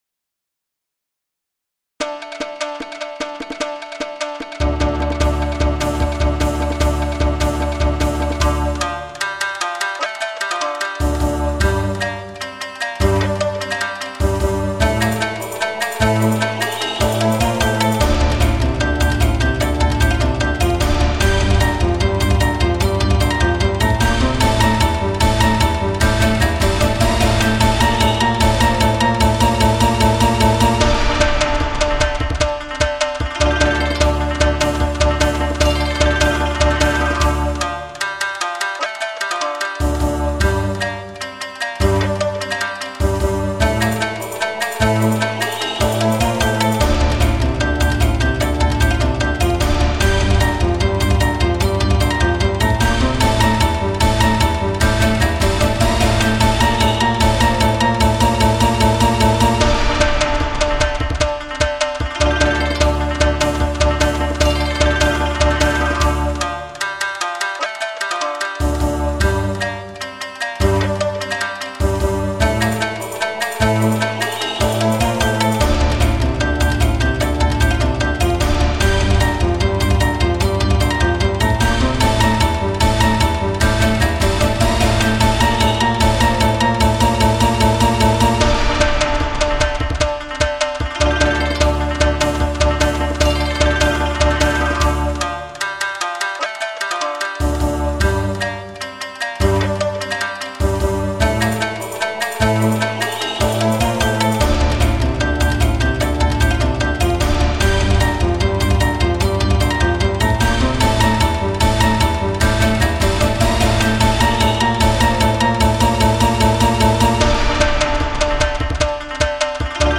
アップロードサイズ制限の都合上mp3の劣化が激しいのはご愛嬌
耳コピ